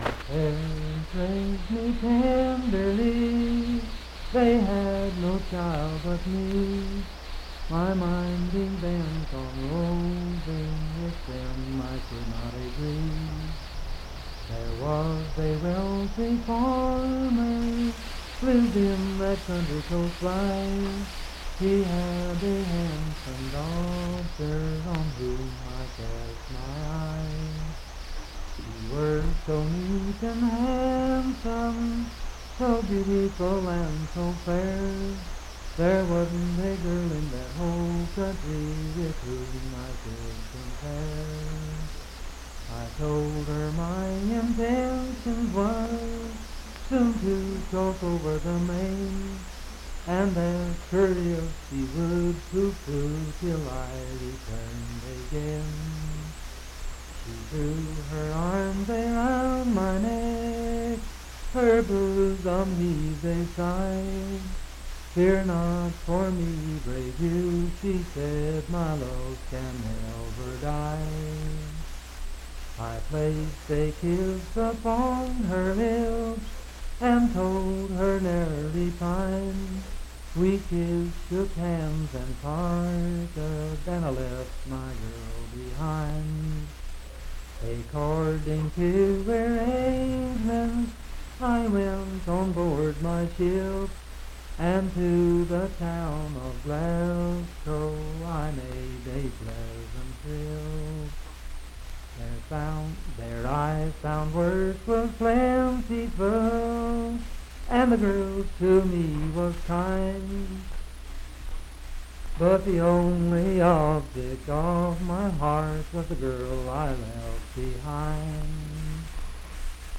Unaccompanied vocal music
Voice (sung)
Pocahontas County (W. Va.), Marlinton (W. Va.)